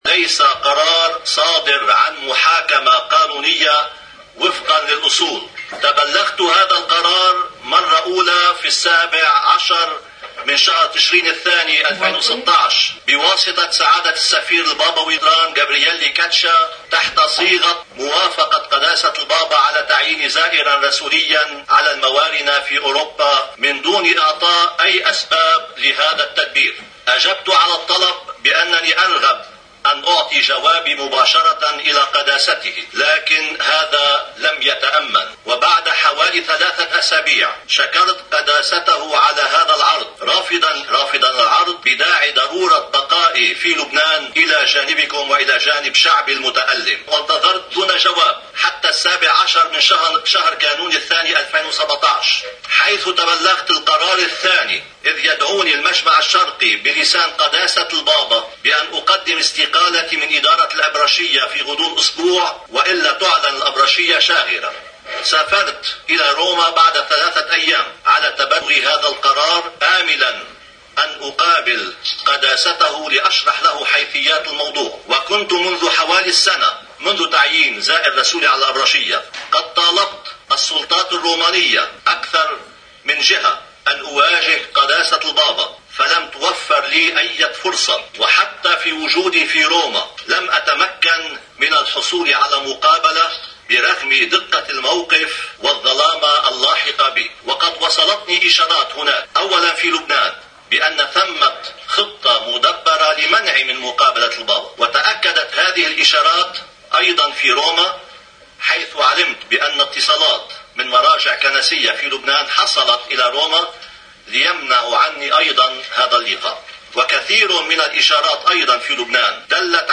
كلمة المطران الياس نصار في المؤتمرالصحفي.. ووضع النقاط على الحروف: